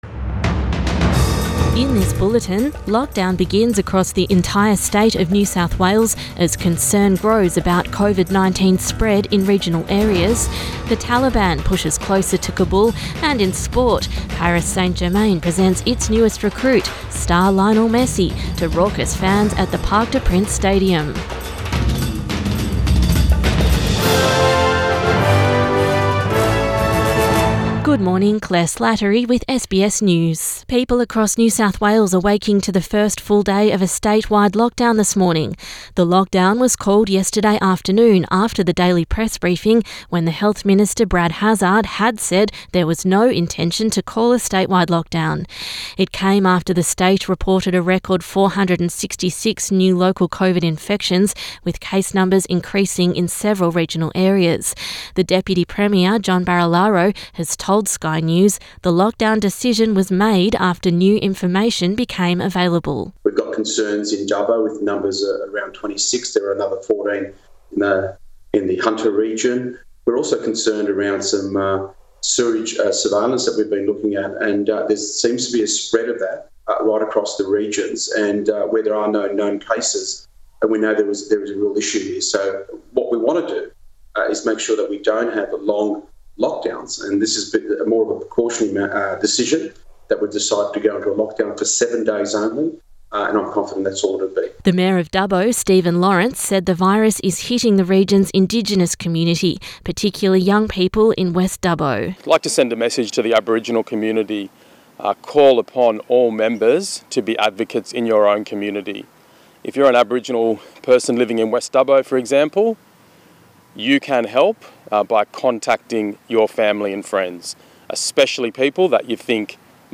AM bulletin 15 August 2021